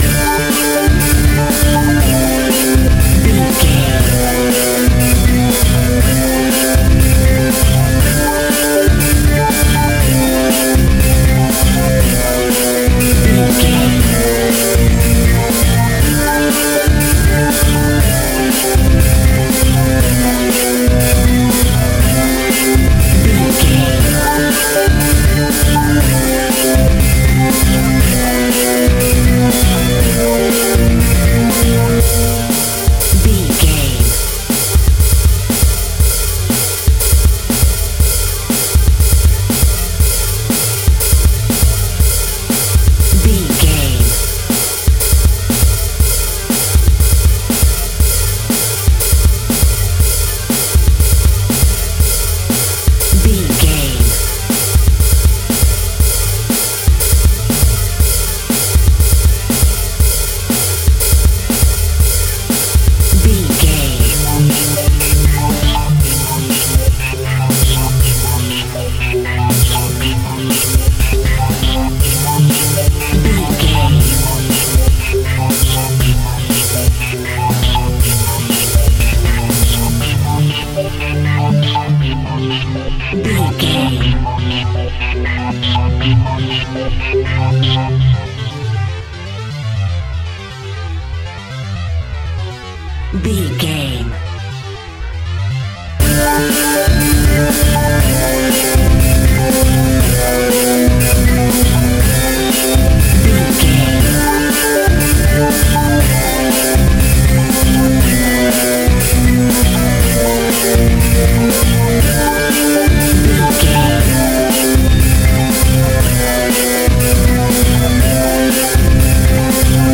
Epic / Action
Fast paced
Aeolian/Minor
heavy rock
hard rock
overdrive
distortion
rock instrumentals
heavy guitars
Rock Drums
Rock Bass